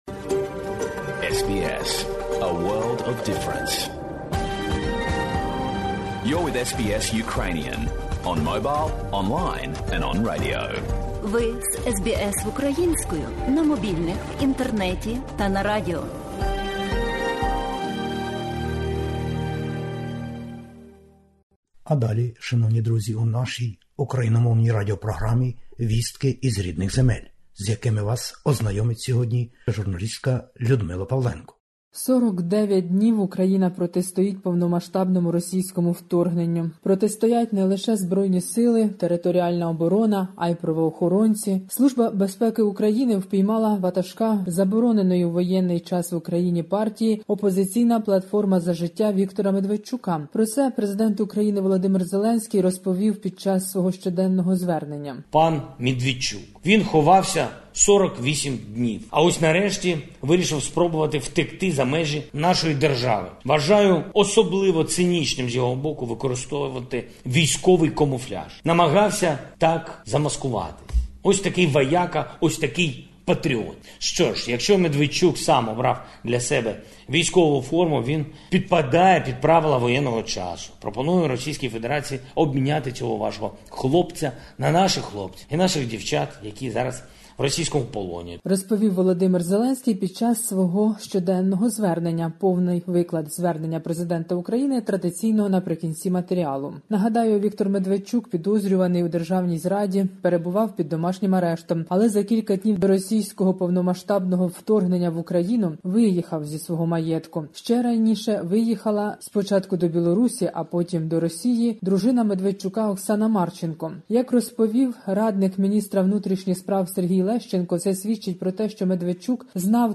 Добірка новин із України спеціально для радіослухачів SBS Ukrainian. Українці мужньо захищаються по всій країні від російських збройних сил.